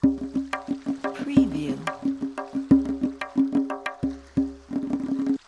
سمپل ریتم تنبک | دانلود 350 سمپل تنبک با کیفیت عالی
سمپل ریتم تنبک | دانلود سمپل تنبک انواع ریتم های 3/4 - 4/4 - 6/8 با کیفیت فوق العاده بالا و نوازندگی بی نظیر
demo-tombak.mp3